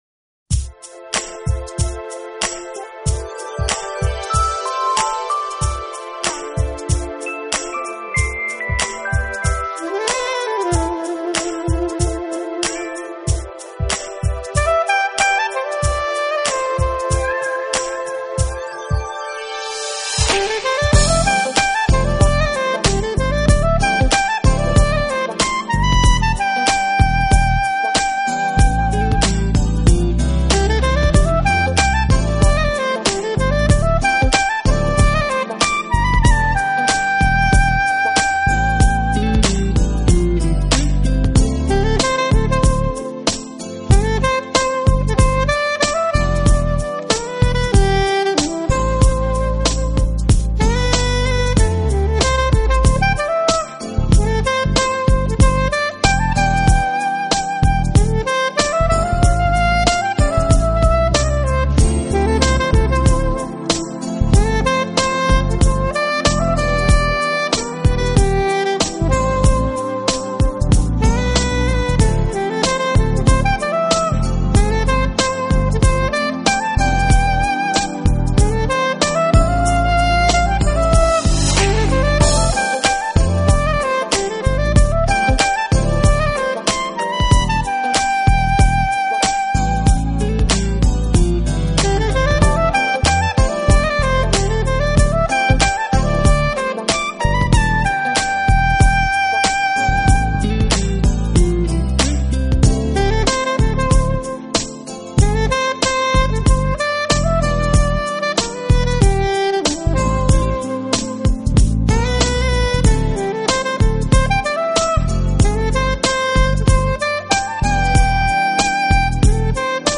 【爵士萨克斯】
专辑风格：Smooth Jazz
的旋律，还有他那充满深情的蓝调律动，给人带来的是震撼。